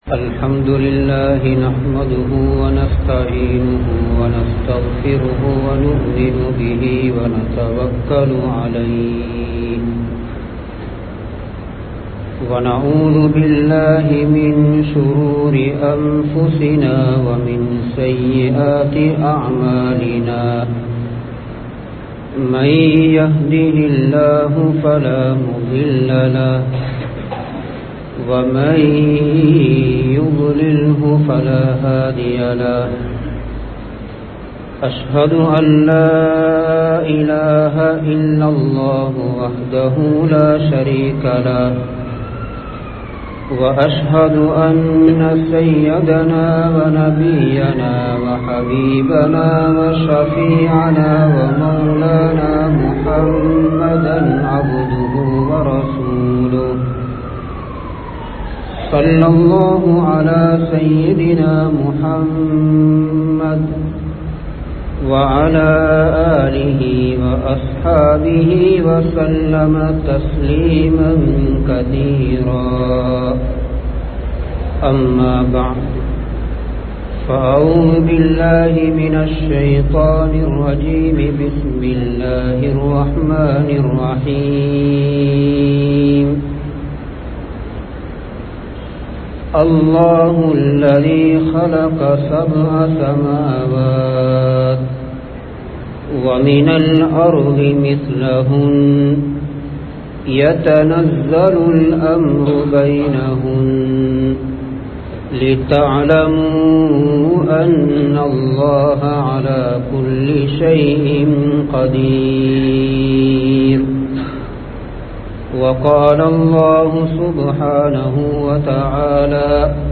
பூமியின் நிலைகள் மாறக் காரணம் என்ன? | Audio Bayans | All Ceylon Muslim Youth Community | Addalaichenai
Mallawapitiya Jumua Masjidh